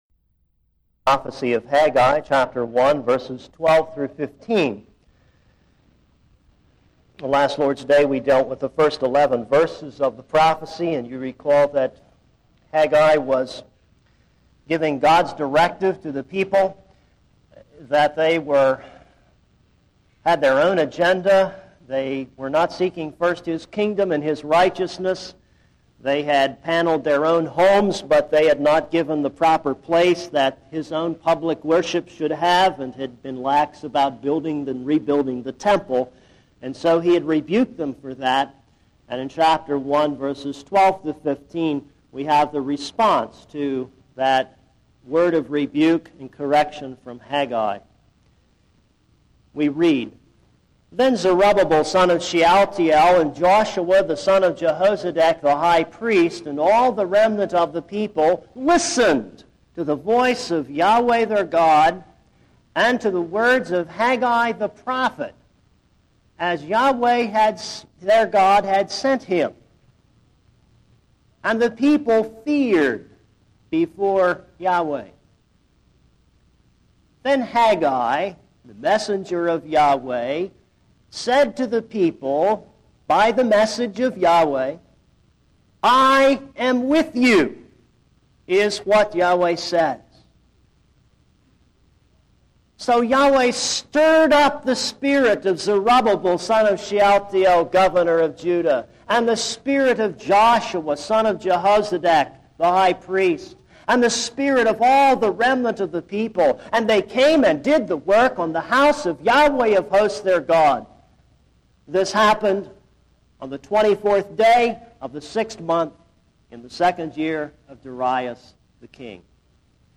This is a sermon on Haggai 1:12-15.